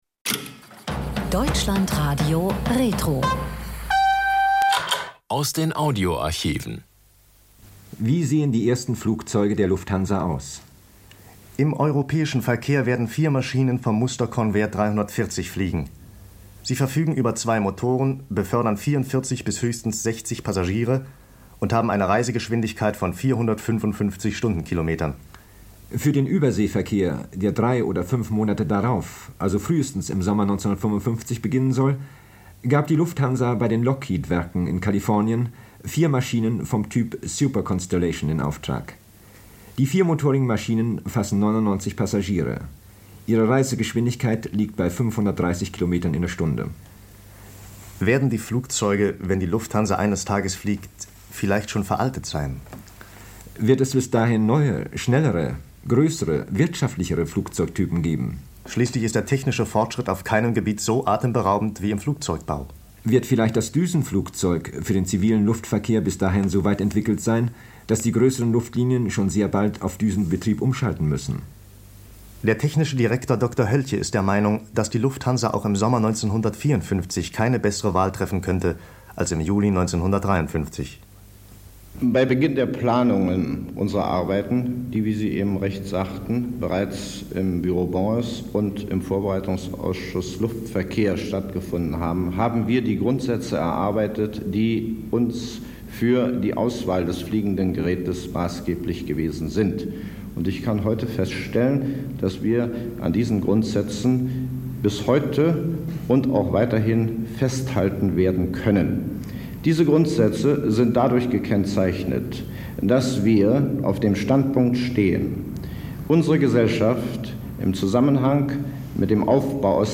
Mitarbeiter berichten aus erster Hand.